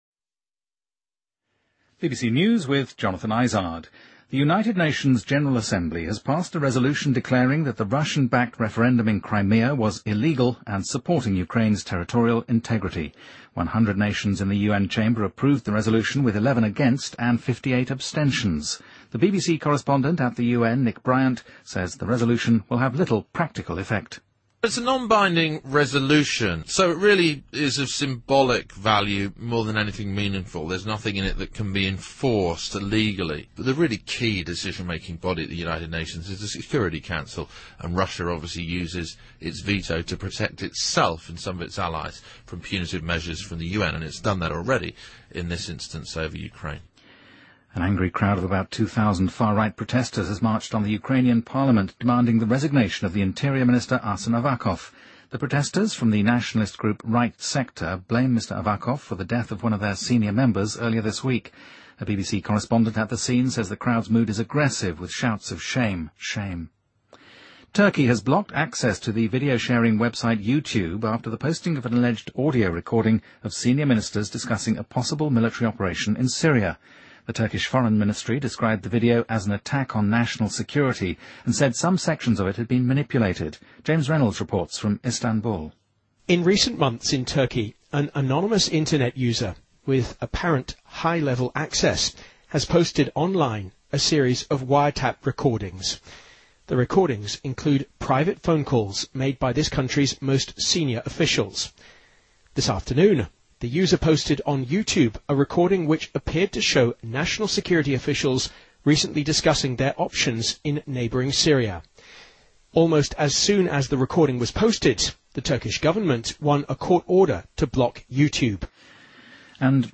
BBC news,2014-03-28